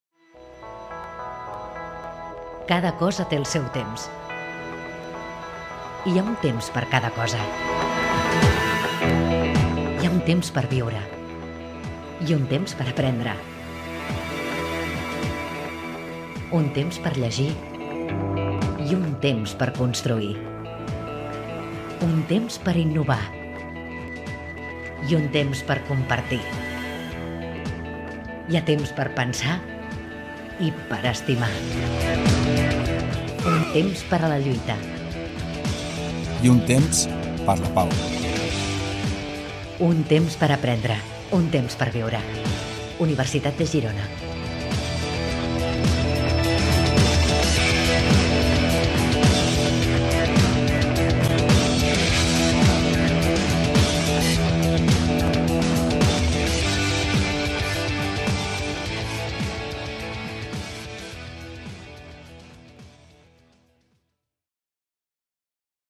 Vídeo publicitari de la Universitat de Girona